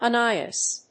/—ˈeɪ.nɪ.əs(米国英語)/